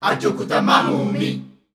Index of /90_sSampleCDs/Zero G Creative Essentials Vol 27 Voices Of Africa WAV/Voices of Africa Samples/Track 05